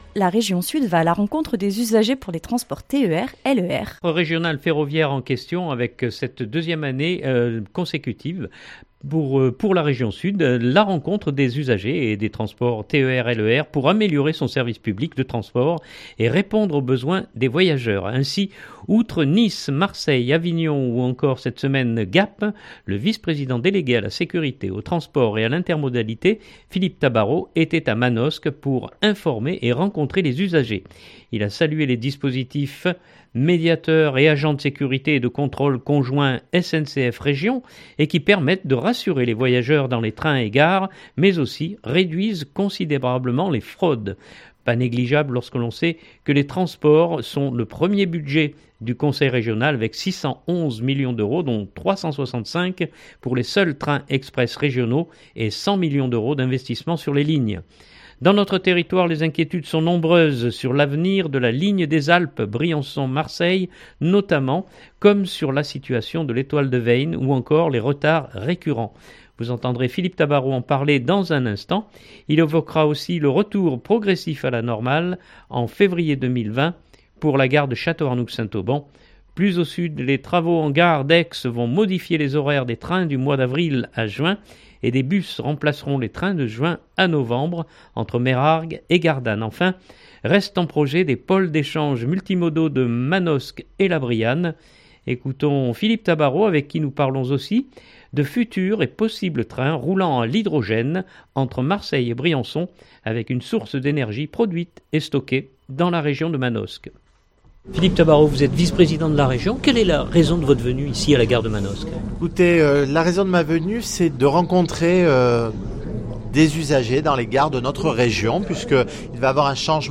Vous entendrez Philippe Tabarot en parler dans un instant. Il évoquera aussi le retour progressif à la normal en février 2020 pour la gare de Château-Arnoux Saint Auban.
Ecoutons Philippe Tabarot avec qui nous parlons aussi de futurs et possibles trains roulant à l’hydrogène entre Marseille et Briançon avec une source d’énergie produite et stockée dans la région de Manosque.